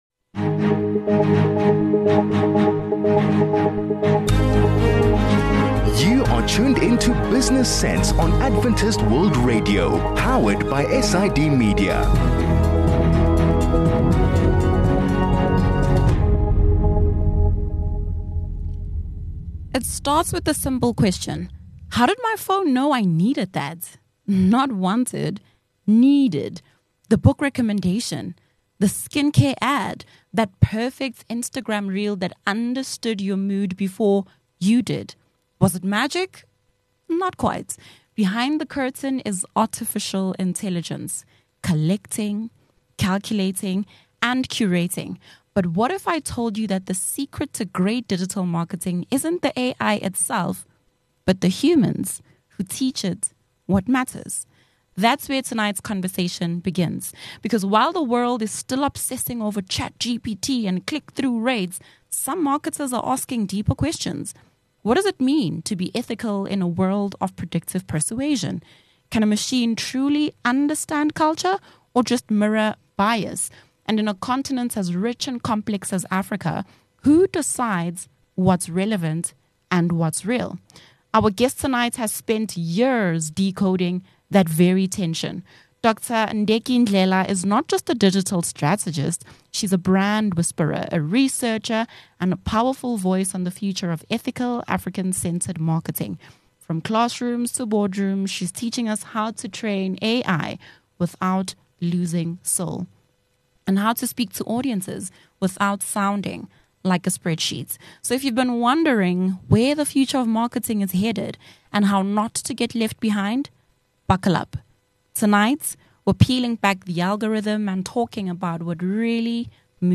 Artificial Intelligence (AI) in digital marketing has evolved from a fantastical notion to a game-changer for today's marketers. In this conversation, we'll break down what AI means for digital marketing, examples of its use, challenges and tools.